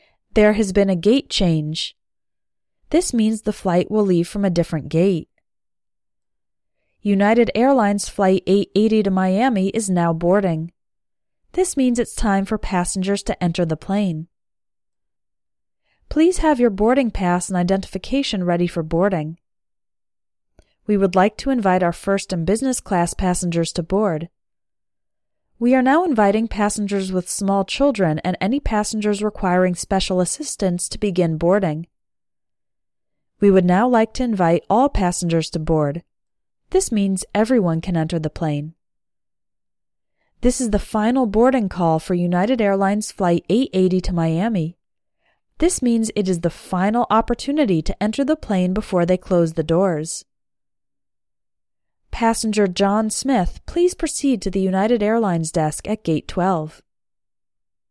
Airport English: Announcements at the Gate
Here are a few announcements you might hear while you are at the gate, waiting for the plane to board.